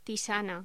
Locución: Tisana